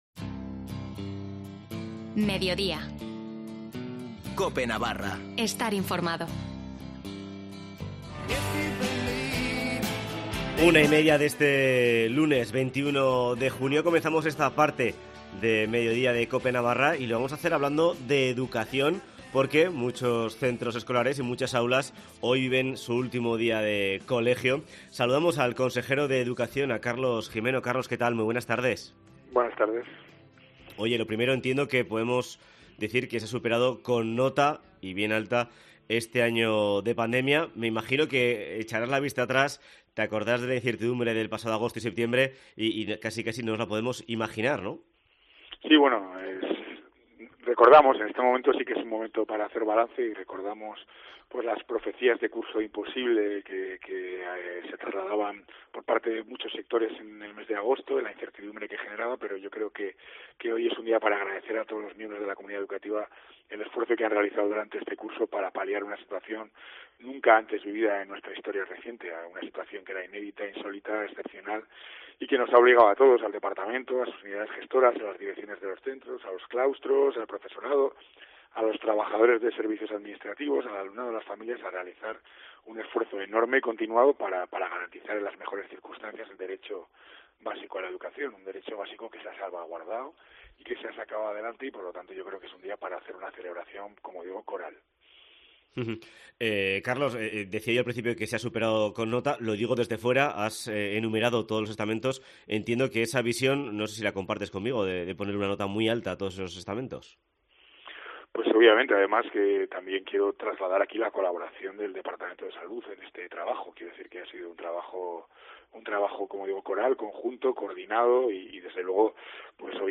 Entrevista a Carlos Gimeno en COPE Navarra